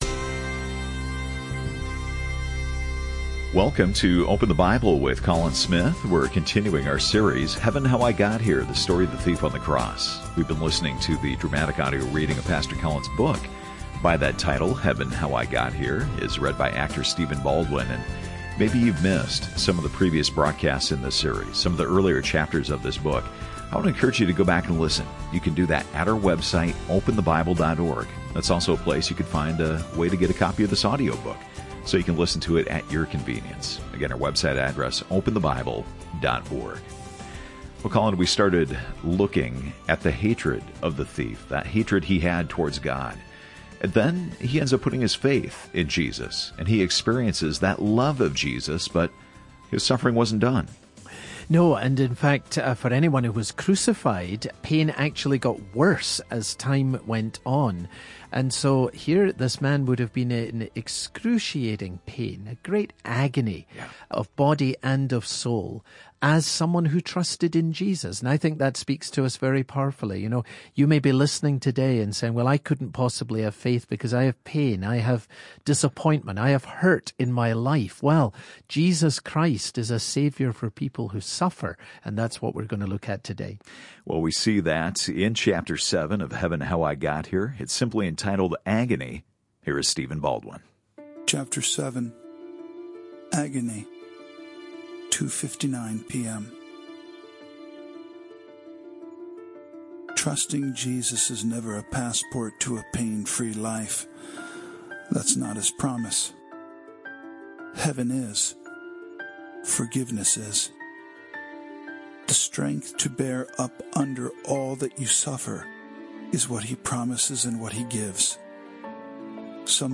This radio broadcast features narration by actor Stephen Baldwin.